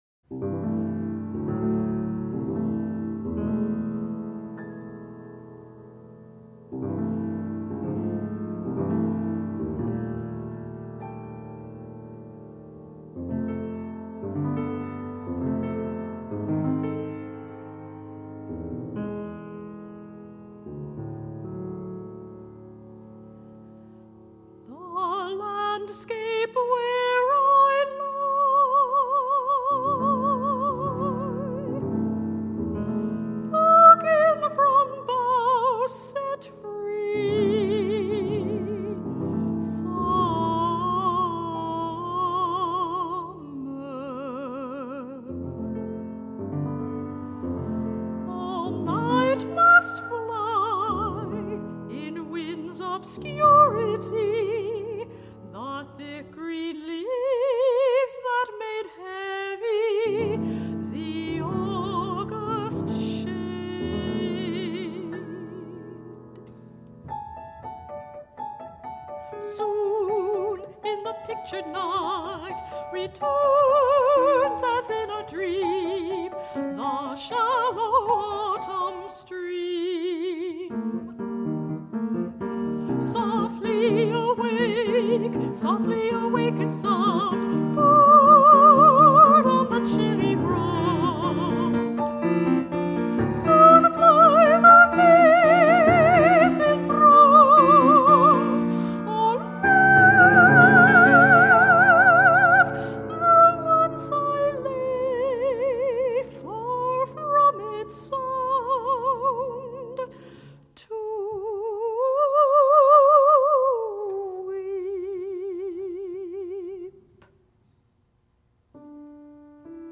for Soprano and Piano (1982)